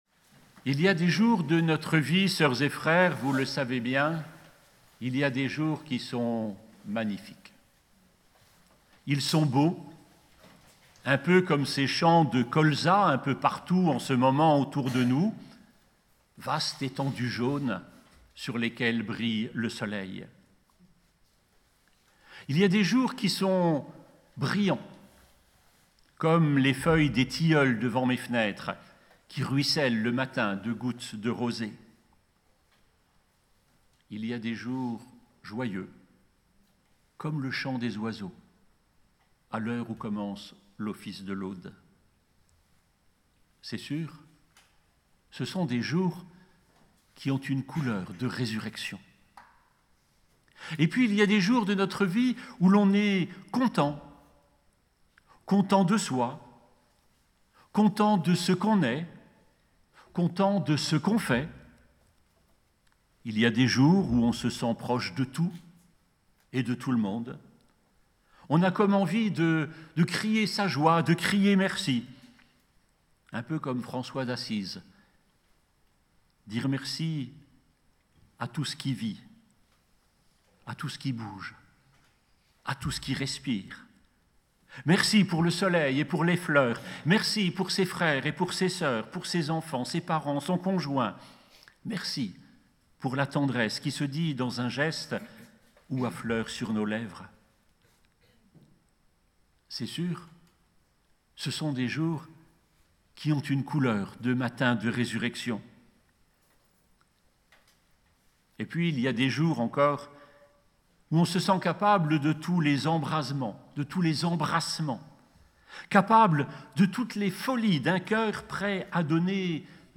PÂQUES 2025 MONASTERE DE PRAILLES